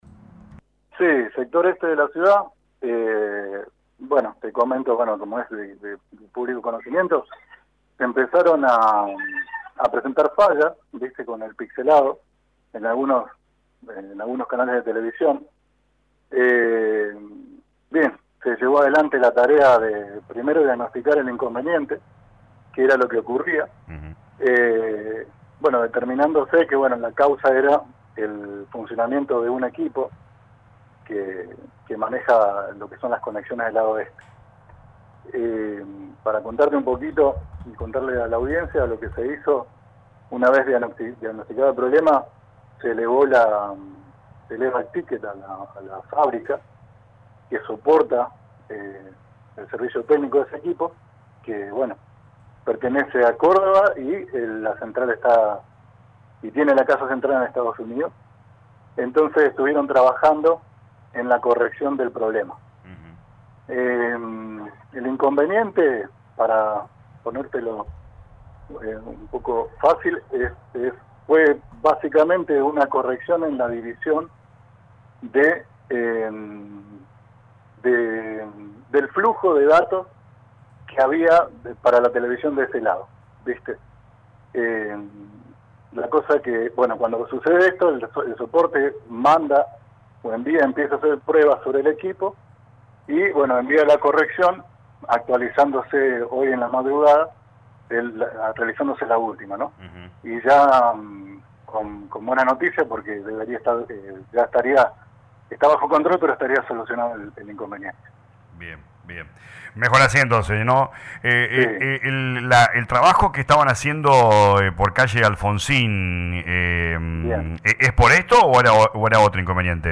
en diálogo con LA RADIO 102.9